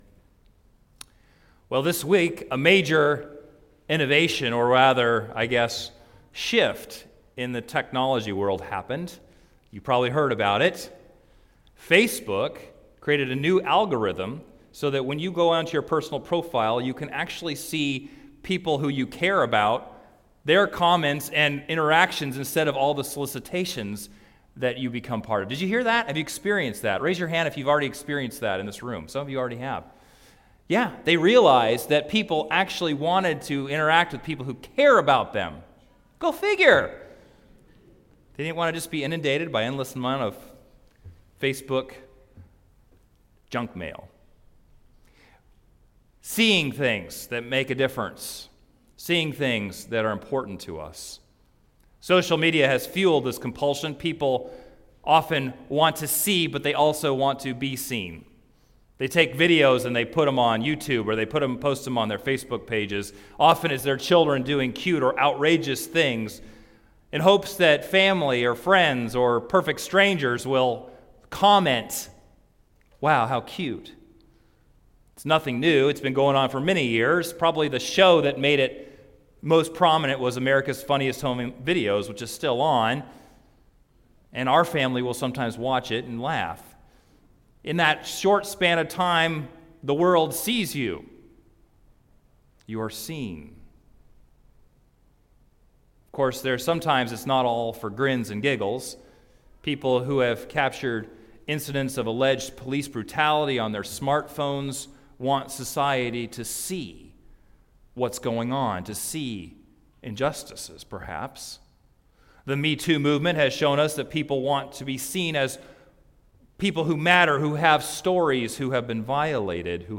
Sermon-1.14.18.mp3